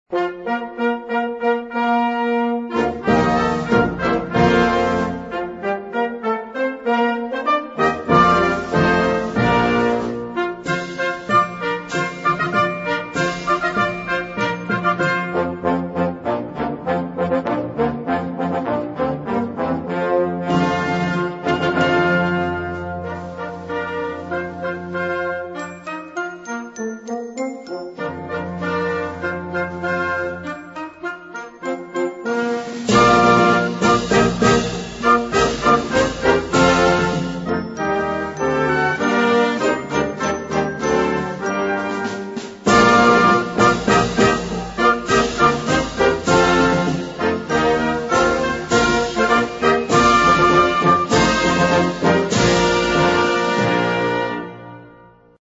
Bezetting Flexi (variabele bezetting); (4)